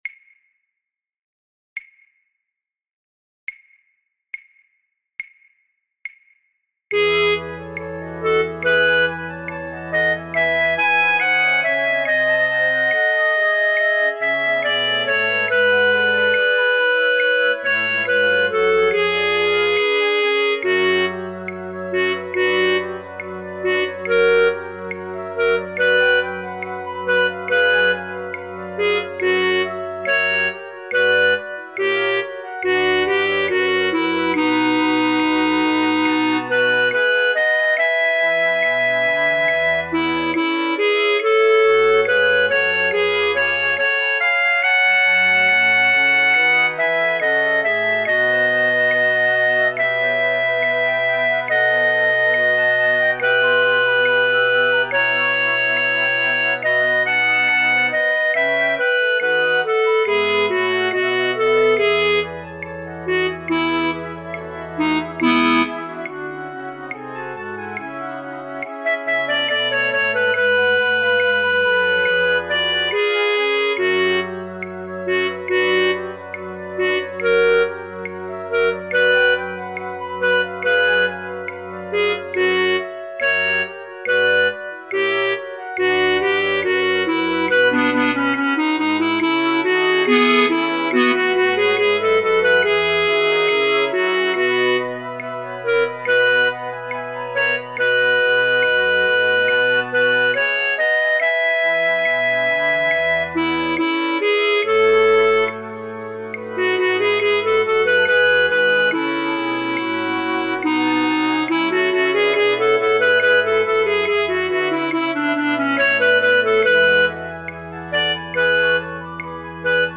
alle partijen